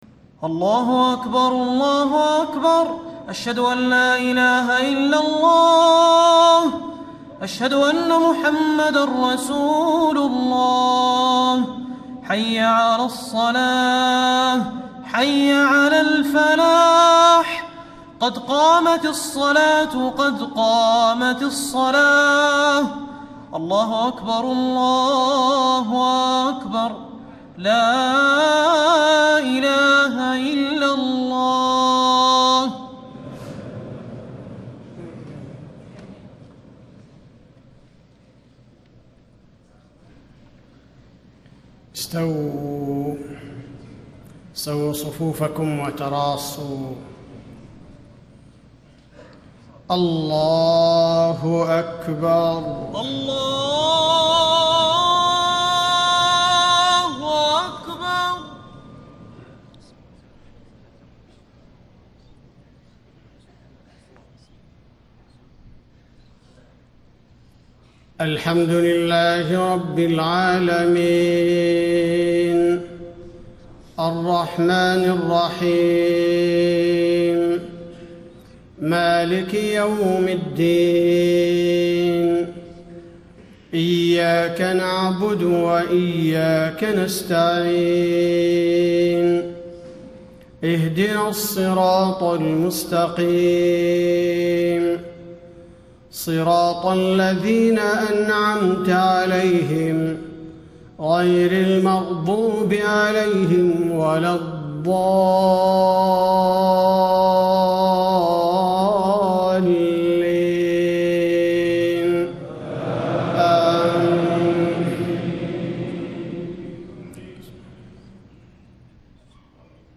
فجر 20 رمضان ١٤٣٥ سورة الملك > 1435 🕌 > الفروض - تلاوات الحرمين